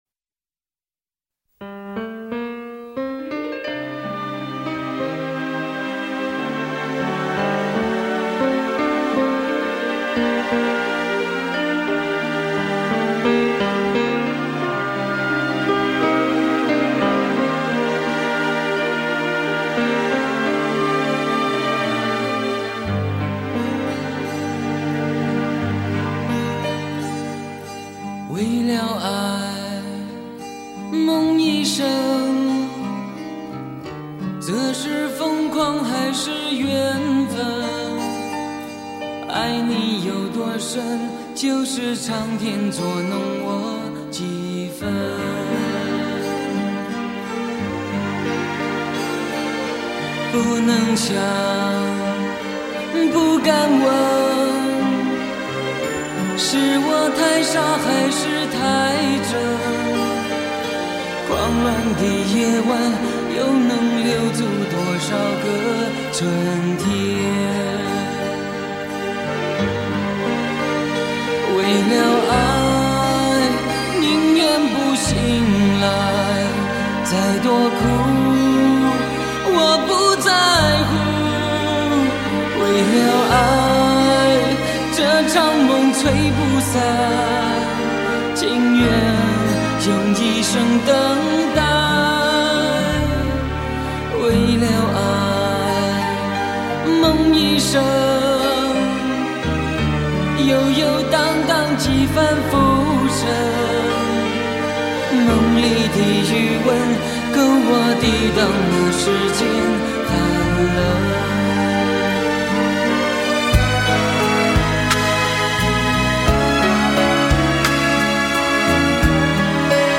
雄伟典范乐曲 必唯天作之合HI-FI典范 极致人声